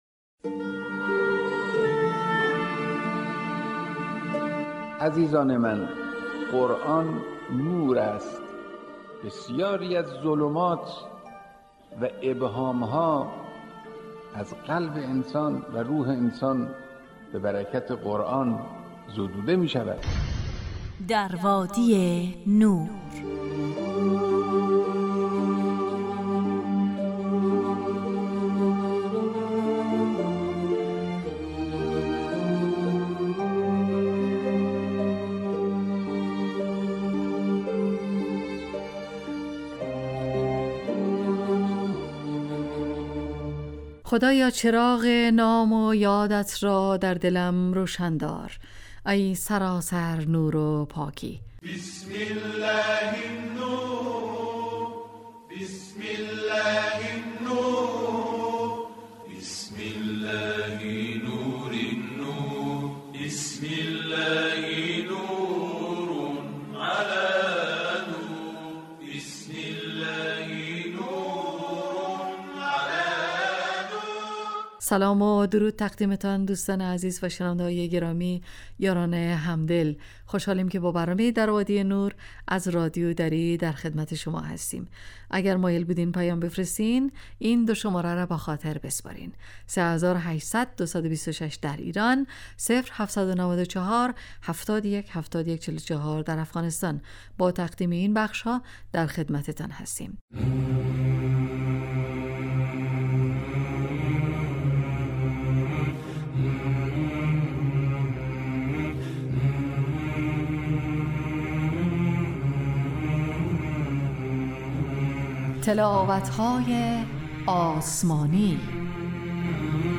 روزهای فرد: ( قرآن و عترت،طلایه داران تلاوت، ایستگاه تلاوت، دانستنیهای قرآنی، تفسیر روان و آموزه های زندگی ساز. روزهای زوج: ( واژه های نور، اسماء الهی، ایستگاه تلاوت، داستان آیات، تفسیر روان و آموزه های زندگی ساز.